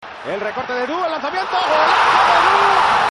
「どう聞いてもエドゥーやな」